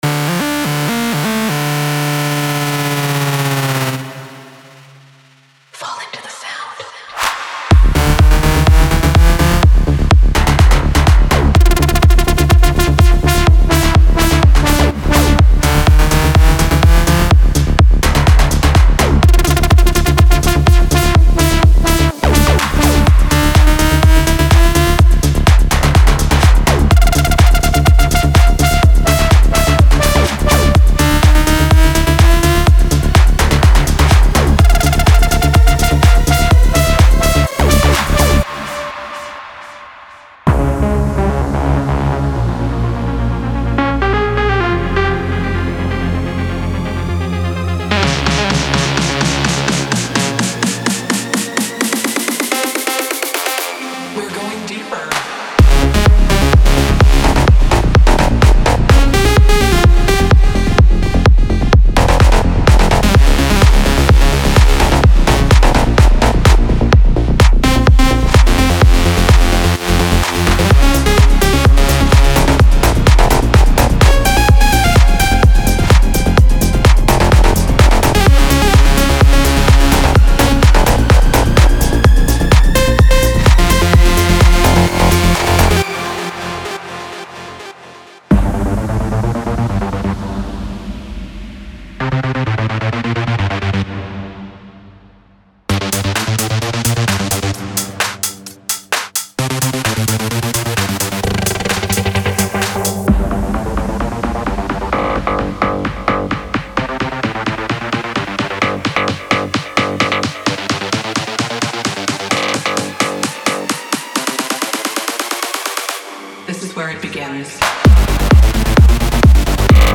デモサウンドはコチラ↓
Genre:Melodic Techno
35 Full Drum Loops, Kick&Snare Loops, Top Loops